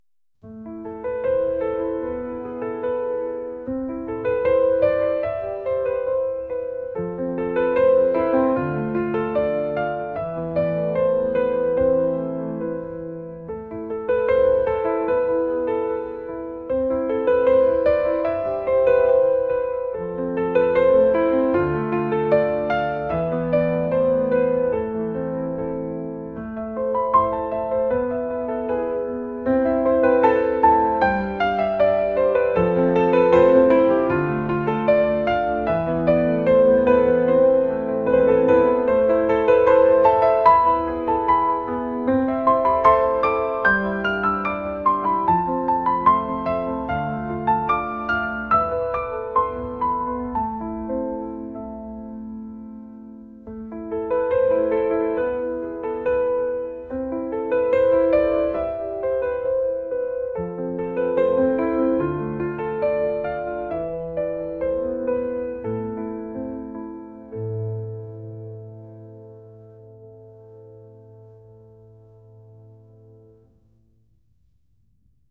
classical | cinematic | ambient